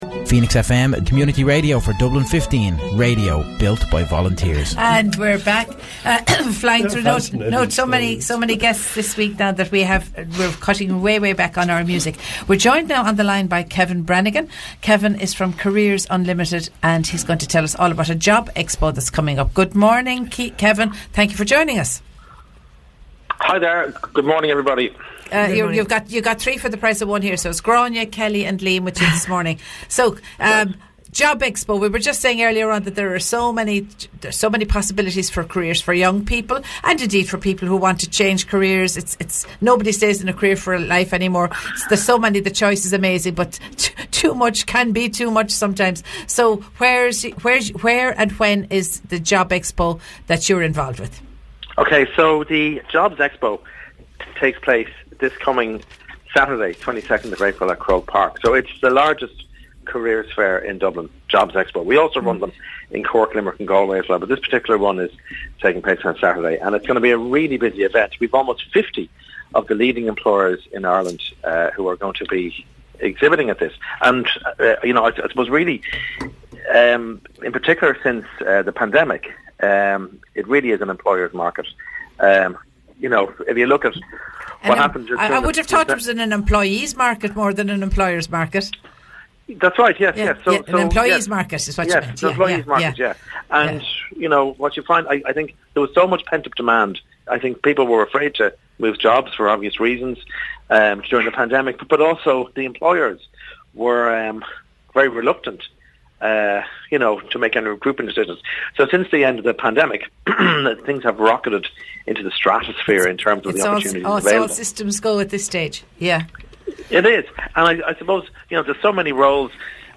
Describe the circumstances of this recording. spoke with 92.5 Phoenix FM in Blanchardstown about the forthcoming Jobs Expo to be held at Croke Park on Saturday 22nd April, 2023.